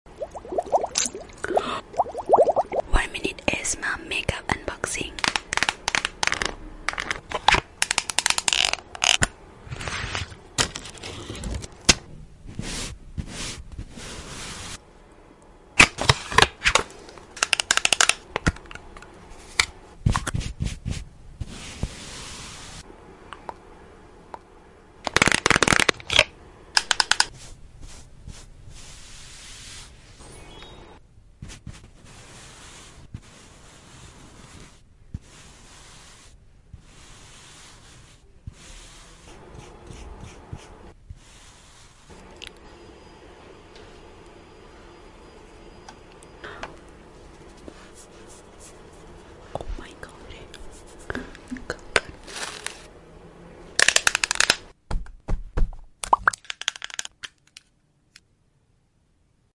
1mins Asmr Makeup Unboxing😍✨ Sound Effects Free Download